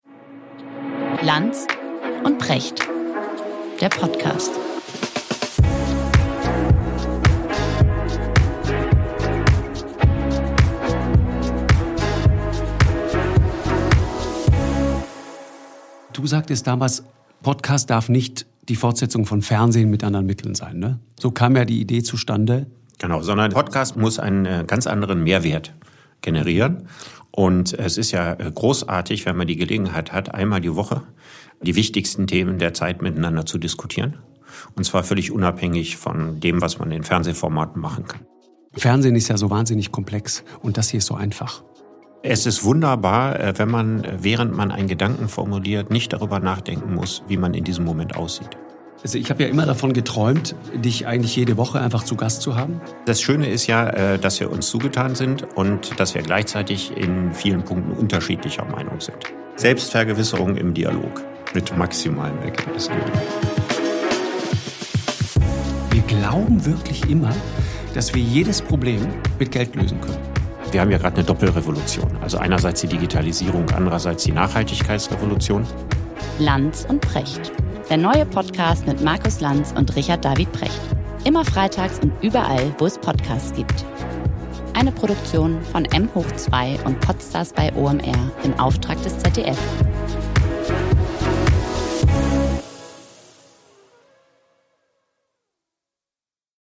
Trailer
Trailer LANZ & PRECHT Download In dem neuen ZDF-Podcast sprechen Markus Lanz (Journalist, Talkshow-Moderator) und Richard David Precht (Philosoph, Schriftsteller) über die gesellschaftlich und politisch relevanten Themen unserer Zeit. Informativ, inspirierend, kontrovers: „LANZ & PRECHT“ ist der wöchentliche Gedankenaustausch zweier Menschen, die sich persönlich zugetan, aber nicht immer einer Meinung sind.